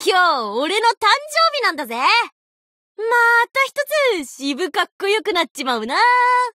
贡献 ） 协议：Copyright，其他分类： 分类:伏特加(赛马娘 Pretty Derby)语音 您不可以覆盖此文件。